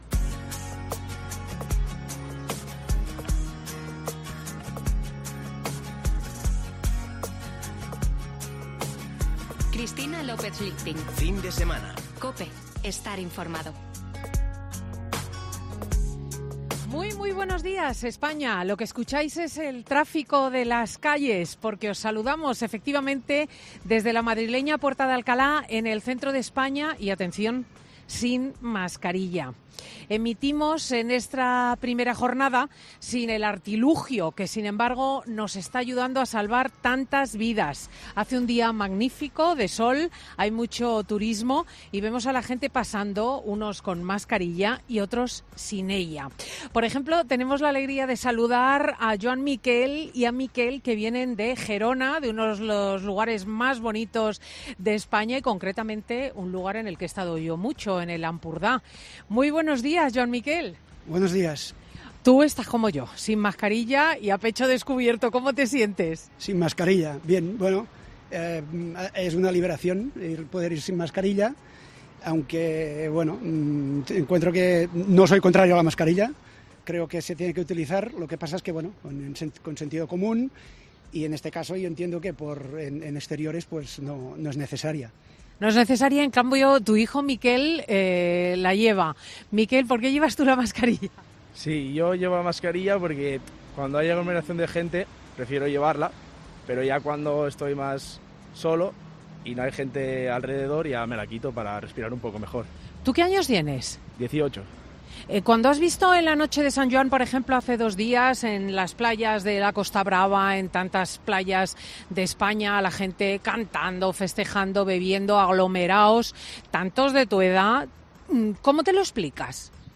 Emitiendo desde la calle en esta primera jornada sin el artilugio que, sin embargo, nos está ayudando a salvar tantas vidas.
Cuéntenos cómo ha sido este largo período de pandemia para usted (preguntaba Cristina a varios ciudadanos que pasaban por el centro de Madrid).